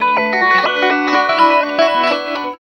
69 GTR 3  -L.wav